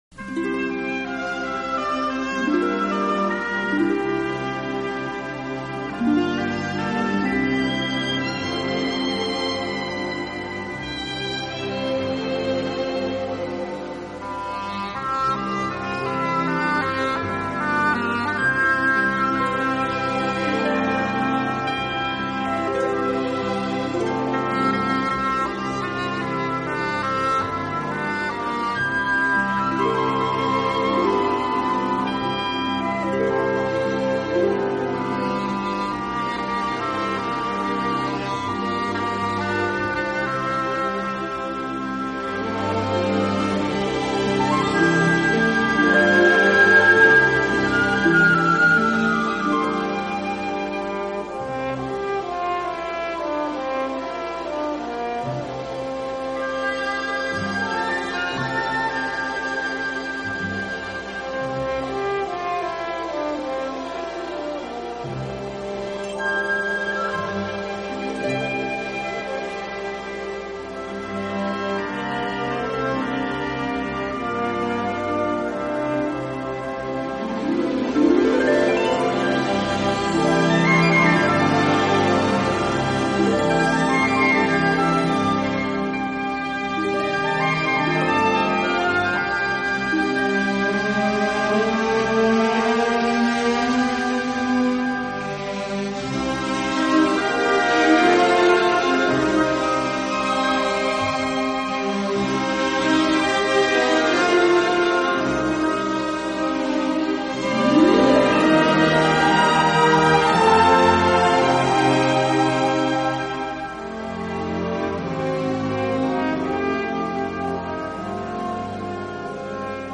【轻音乐专辑】
演奏以轻音乐和舞曲为主。